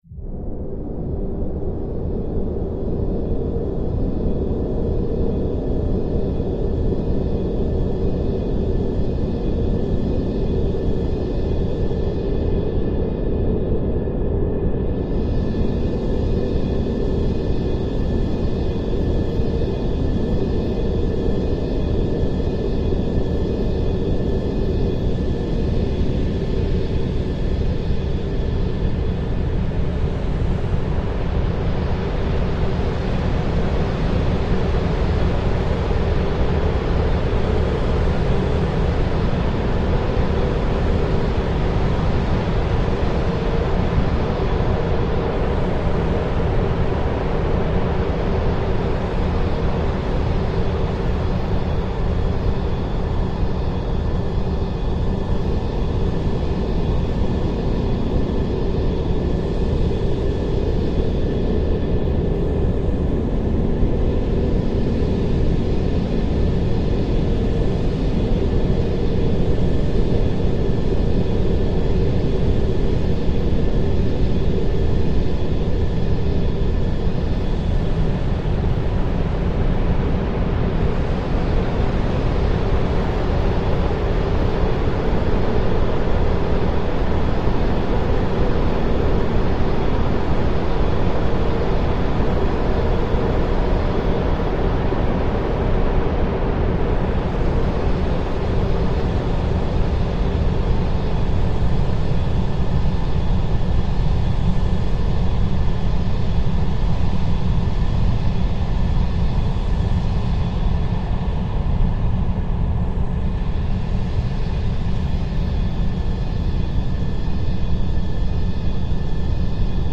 Big Craft Ambience Craft, Sci-fi Space